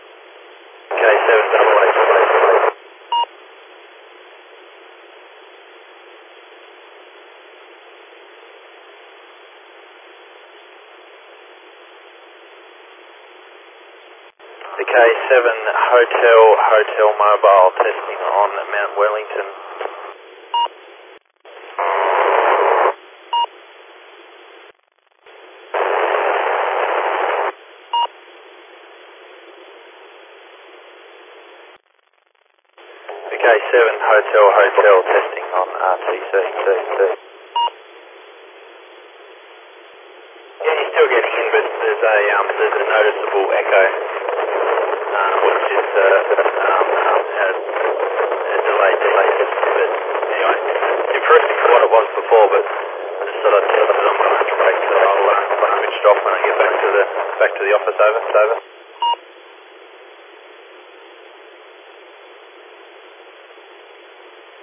[App_rpt-users] Desense (Echo) on RTCM
Attached is a sample audio recording, you can hear the growl of the audio
Name: rtcm desense.mp3